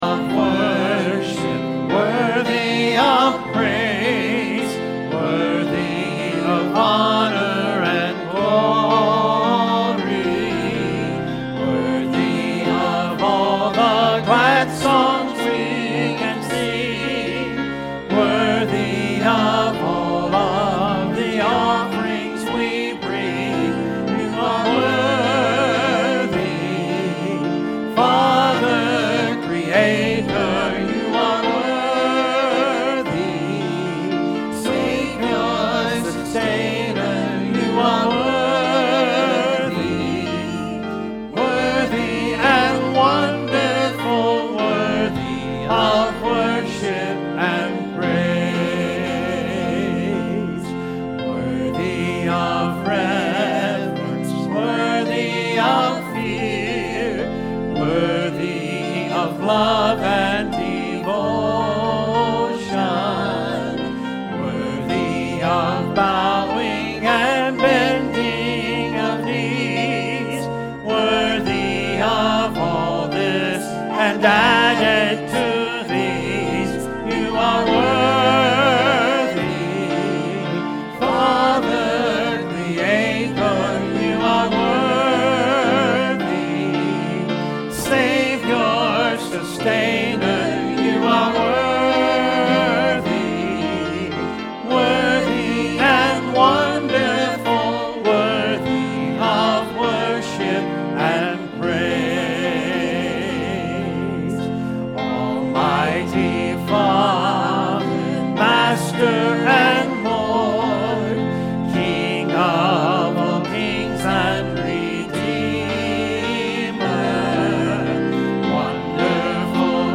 Sunday Sermon May 23, 2021